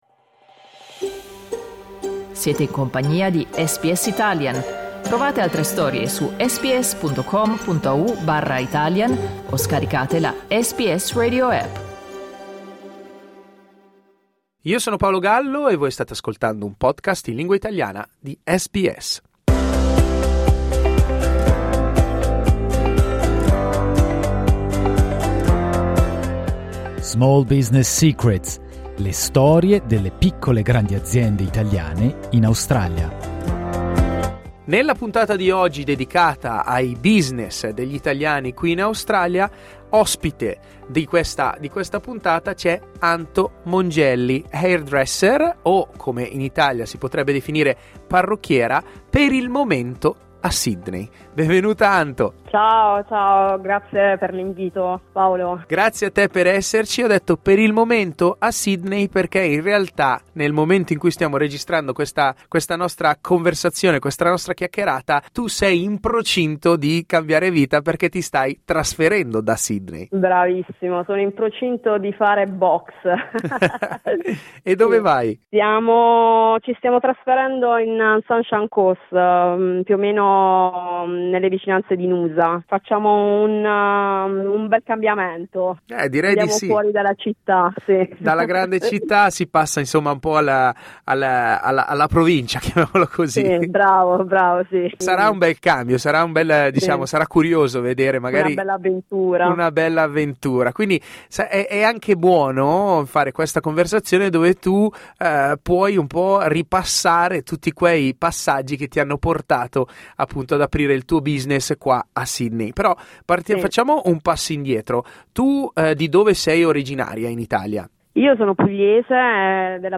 In questa intervista ci racconta i retroscena del mestiere.